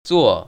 [zuò]
쭈오